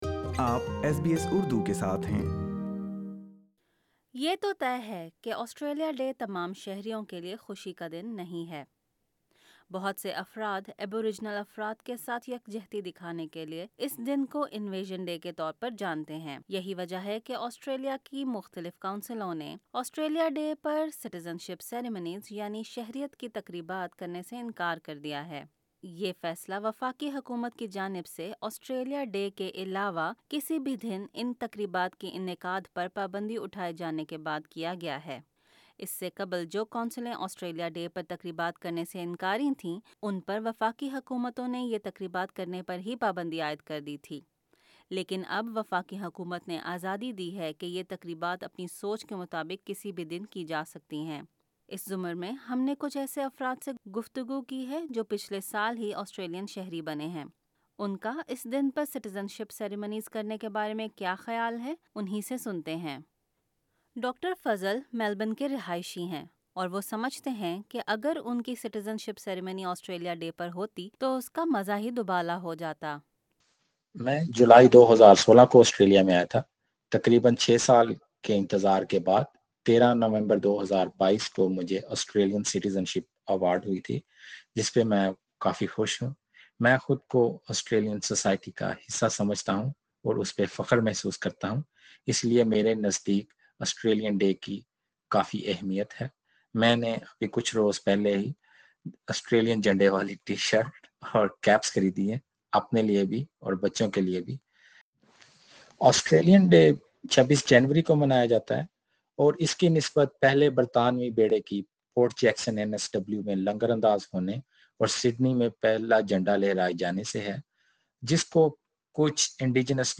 یہی وجہ ہے کہ وفاقی حکومت نے آزادی دی ہے کہ کونسلیں سیٹیزن شپ سیریمنیز یعنی شہریت کی تقریبات اپنی سوچ کے مطابق کسی بھی دن کرسکتی ہیں۔ ہم نے کچھ ایسے افراد سے گفتگو کی ہے جو پچھلے سال ہی آسٹریلین شہری بنے ہیں۔